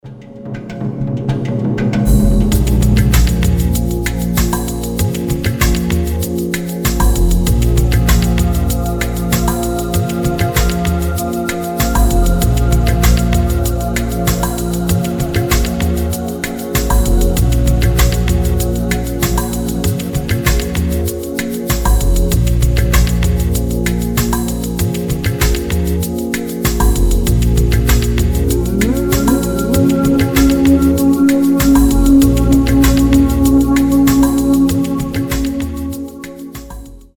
спокойные
без слов
медленные
этнические
Lounge
Ambient